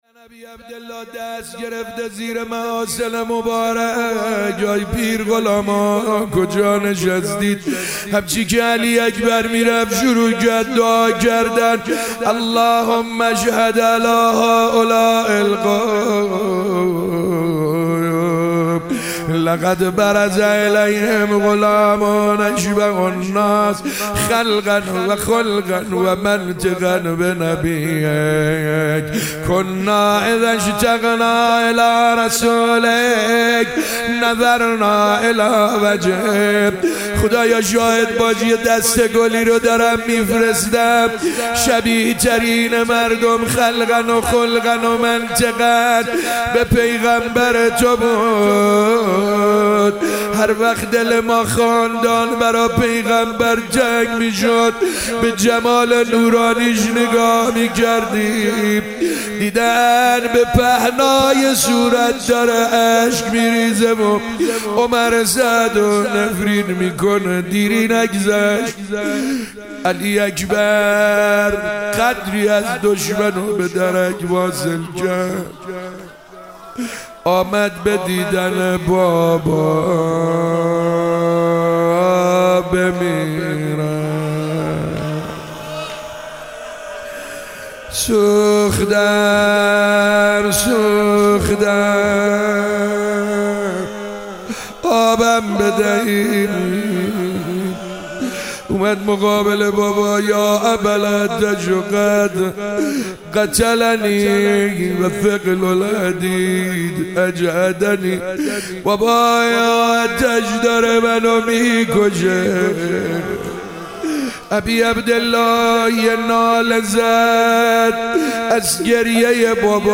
شب هشتم محرم 97 - روضه - حضرت علی اکبر علیه السلام